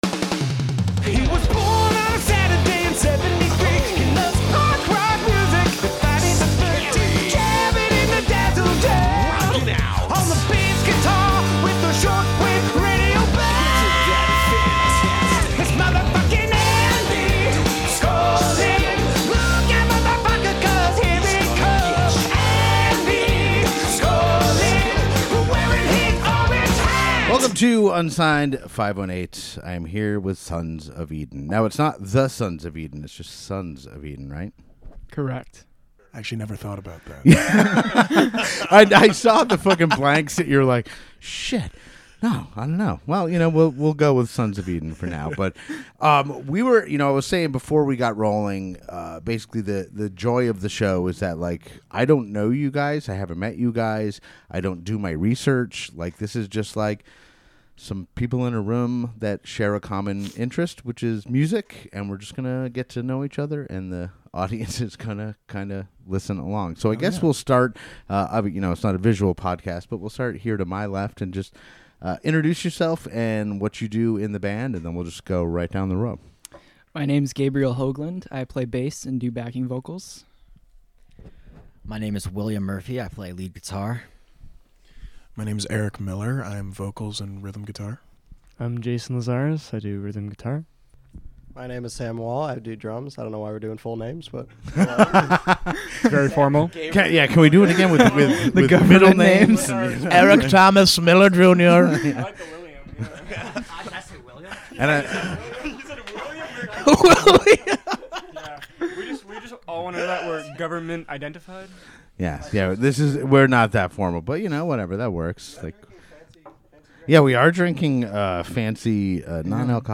Sons of Eden are a relatively new band that formed at Sienna college, but they have the sound, the determination and the songwriting chops to take the band far beyond their former campus halls. Influenced by the grunge sound of the early 90s, Sons of Eden's songs certainly flex that influence while adding their own flair to the mix.